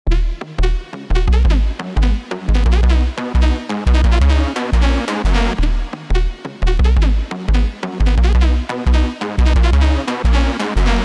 dnb melodic expansion
🔑 Every kit is fully stemmed out, so whether it’s a soaring lead, deep bass, or lush pad that catches your ear, you’ll have complete control over each element.
Stellara Kit D#min (Full)